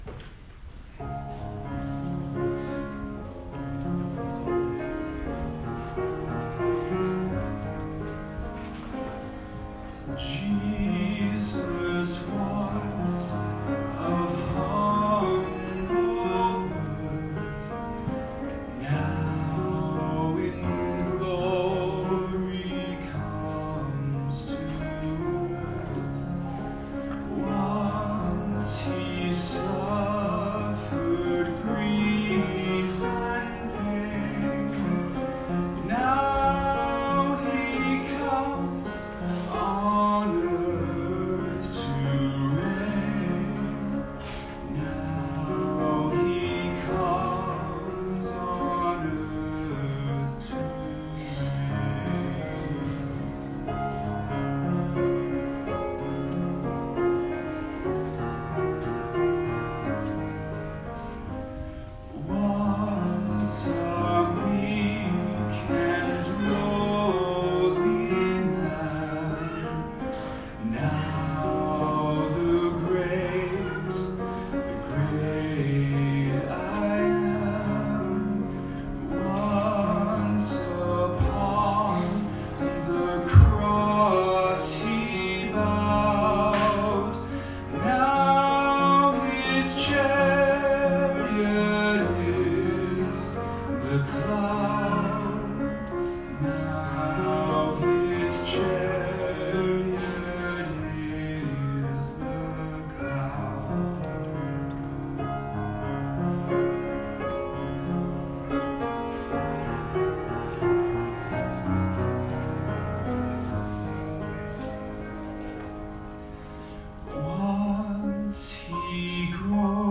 Vocal solo and piano.
A reverent setting with some very nice harmonies in the accompaniment, especially the last line. The vocal range is just over an octave (from D-flat up to E-natural).